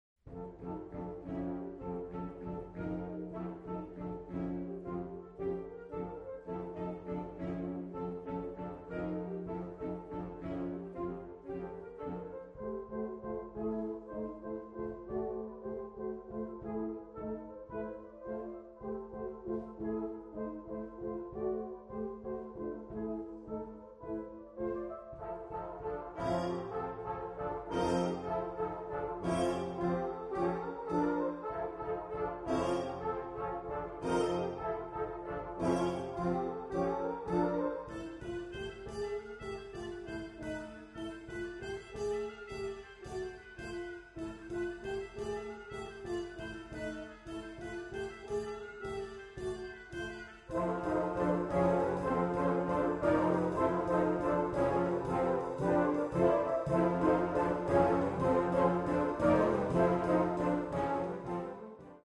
Dit zeer ritmisch en swingend werk